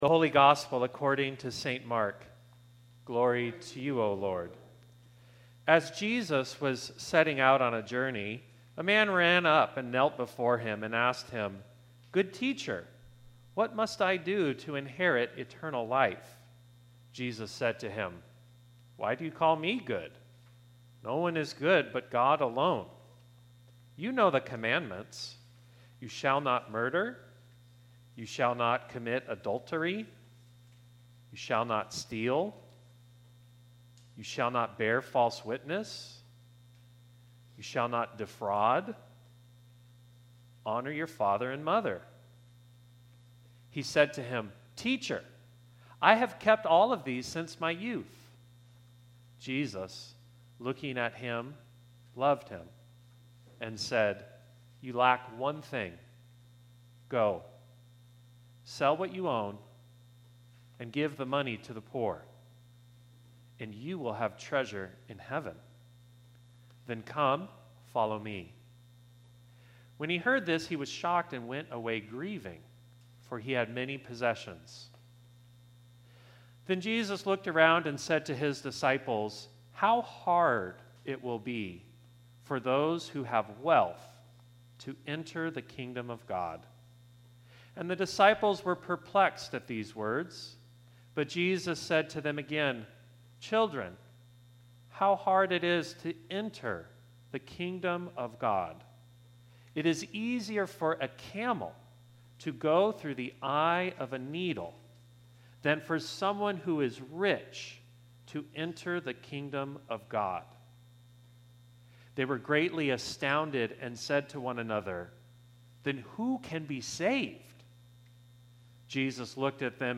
Simply Grace Falling On Our Swords, Sermon 29 May 2022 Play Episode Pause Episode Mute/Unmute Episode Rewind 10 Seconds 1x Fast Forward 30 seconds 00:00 / 00:21:35 Subscribe Share RSS Feed Share Link Embed